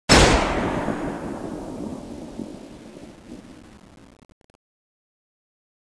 gunshot.wav